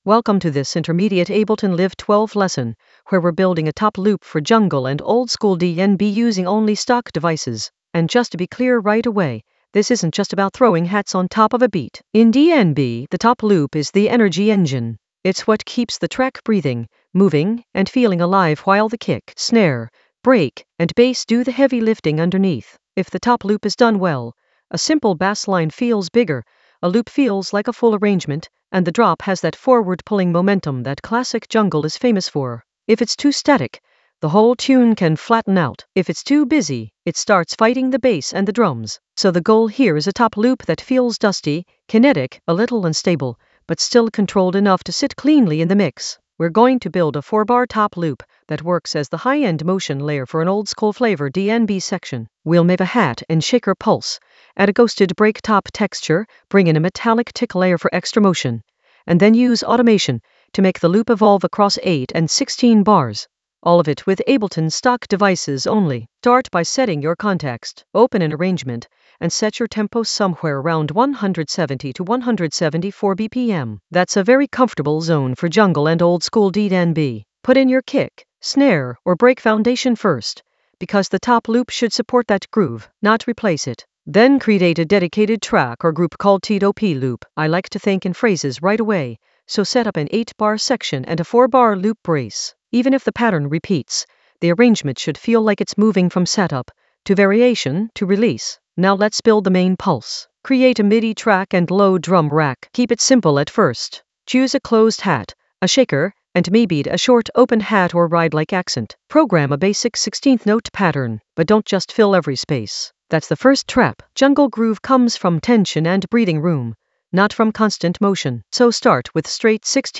An AI-generated intermediate Ableton lesson focused on Guide for top loop using stock devices only in Ableton Live 12 for jungle oldskool DnB vibes in the Arrangement area of drum and bass production.
Narrated lesson audio
The voice track includes the tutorial plus extra teacher commentary.